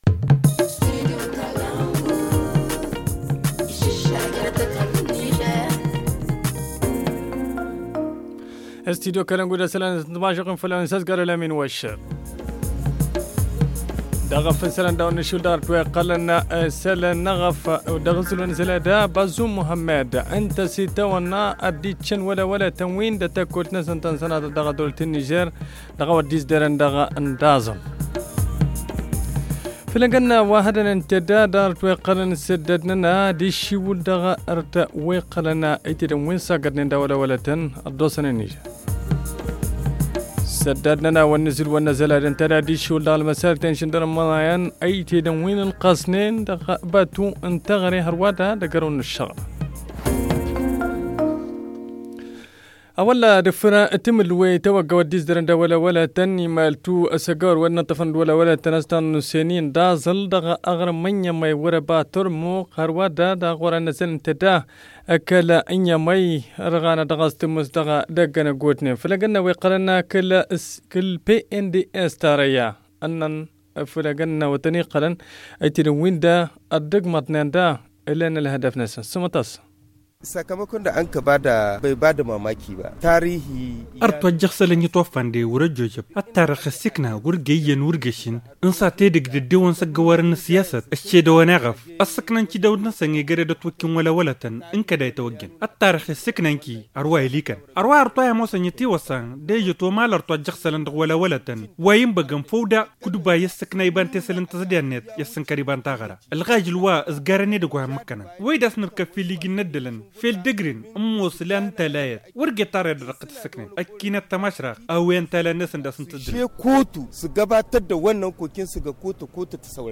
Le journal du 24 février 2021 - Studio Kalangou - Au rythme du Niger